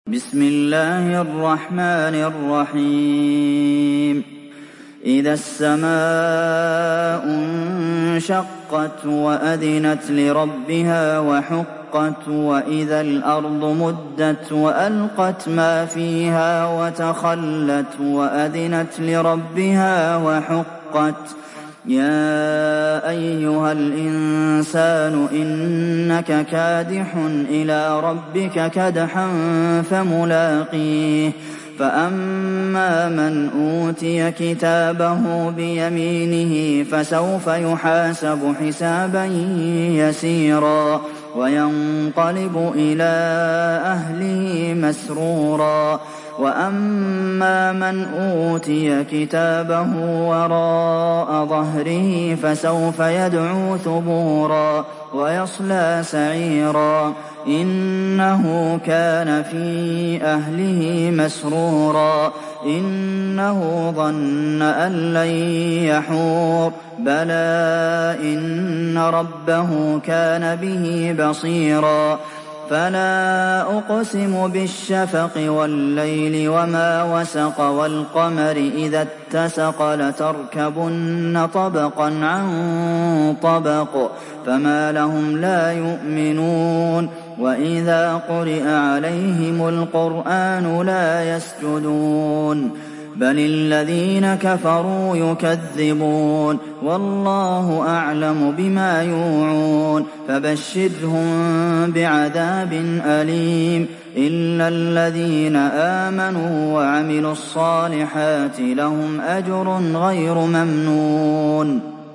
دانلود سوره الانشقاق mp3 عبد المحسن القاسم روایت حفص از عاصم, قرآن را دانلود کنید و گوش کن mp3 ، لینک مستقیم کامل